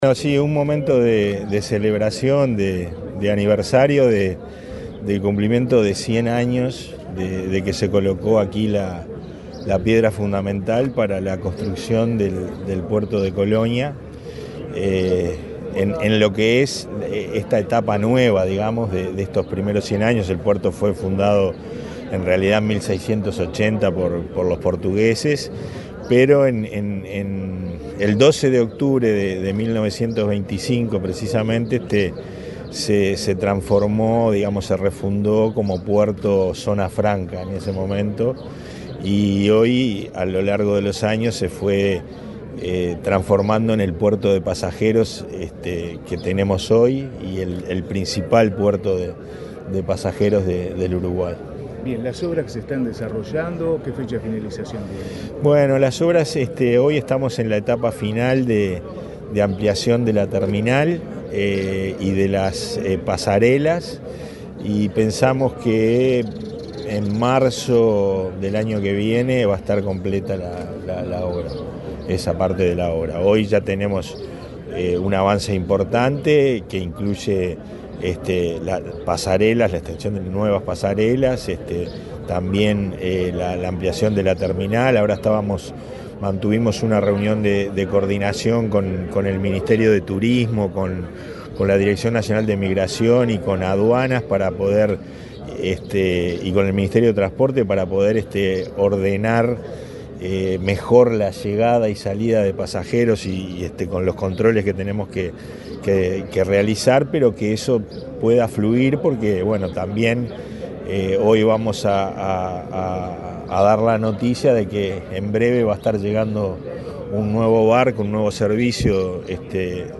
Declaraciones del presidente de ANP, Pablo Genta
Este jueves 30, el presidente de la Administración Nacional de Puertos (ANP), Pablo Genta, dialogó con la prensa, durante su participación en la